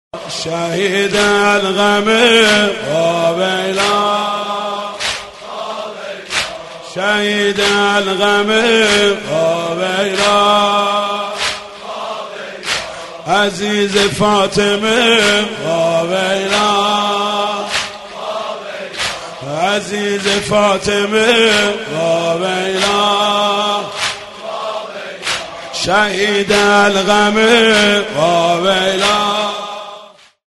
رینگتون عزا